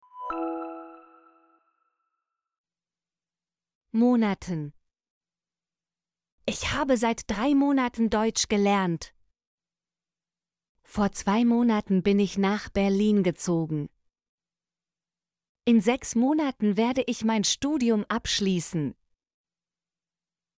monaten - Example Sentences & Pronunciation, German Frequency List